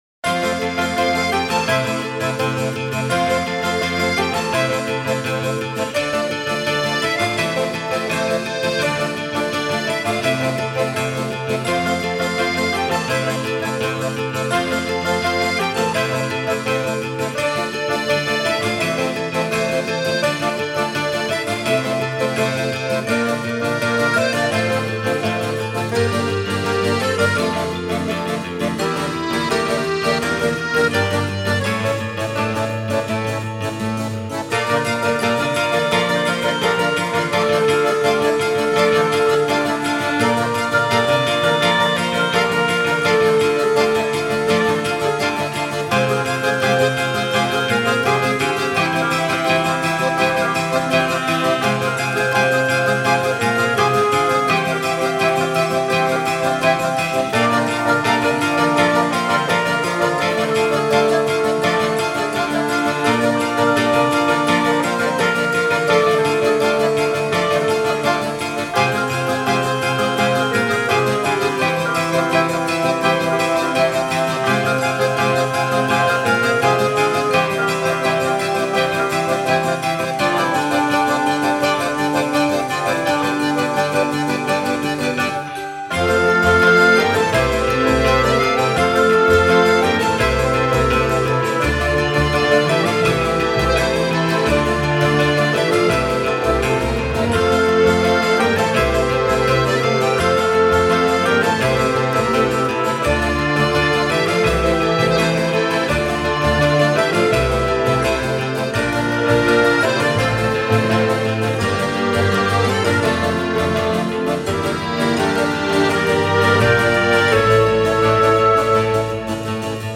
音乐类型:Original Soundtrack
层层叠叠的钢琴、小提琴、手风琴、鼓声、口琴
主旋律带出一次次变奏，旋律就这般悠然荡漾。